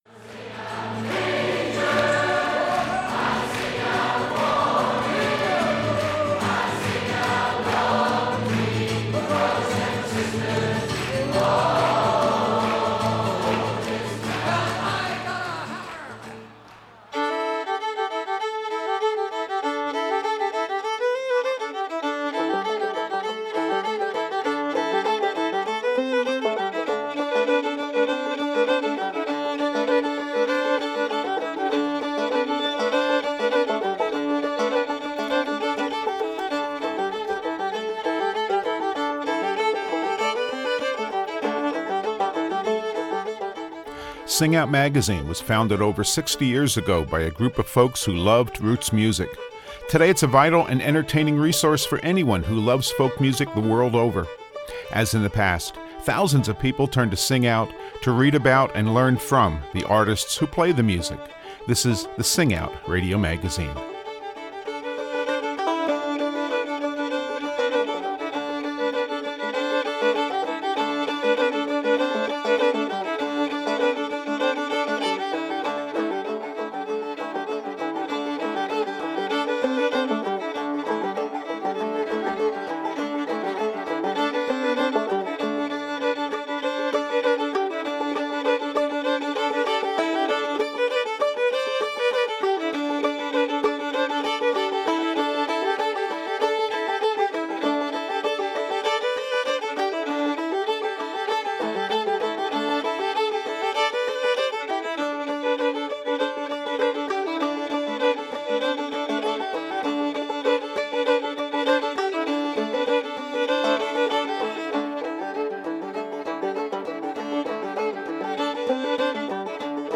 Duets in song and tune